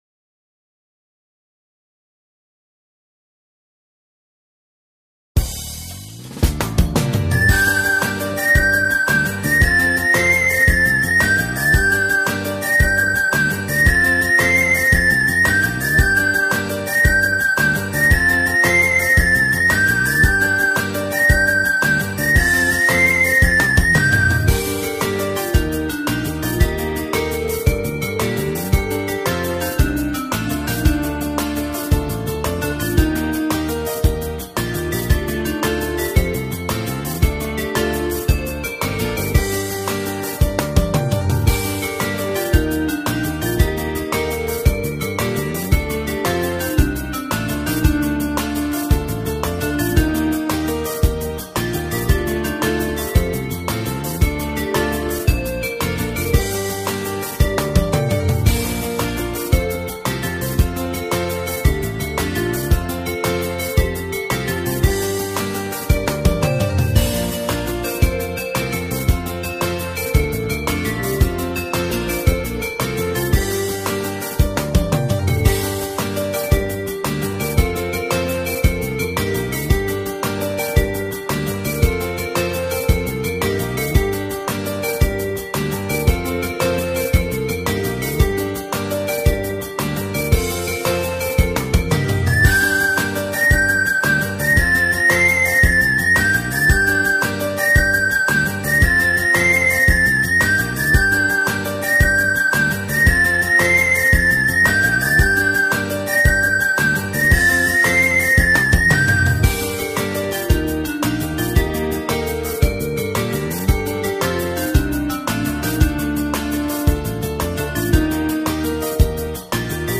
Şarkının sözsüz (enstrumantal) halini indirmek için